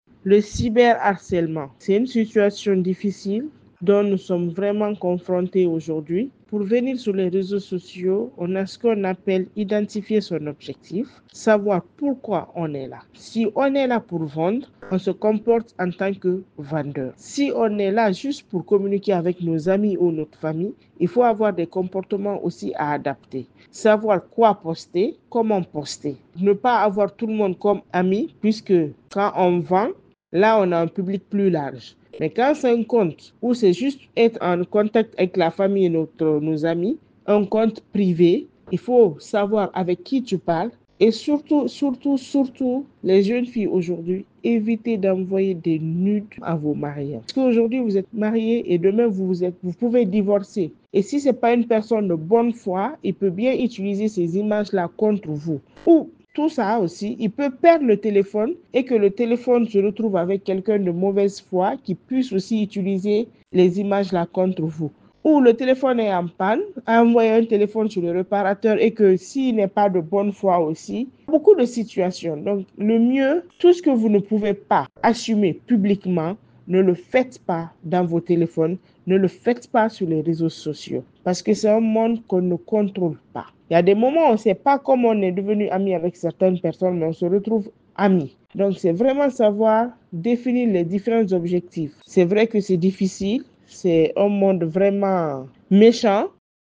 Consultante et formatrice sur le digital, ses propos sont recueillis